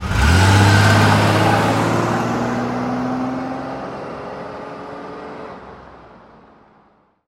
pullaway_out4.wav